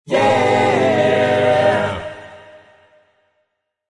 Download Free Yeah Sound Effects